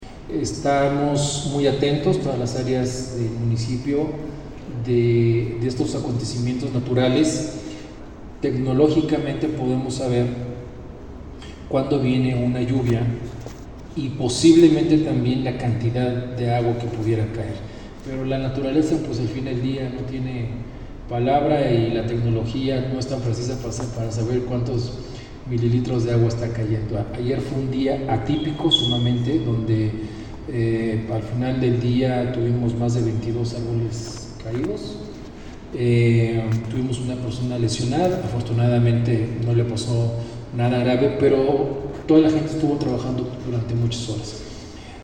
AudioBoletines
Ricardo Benavides, secretario de seguridad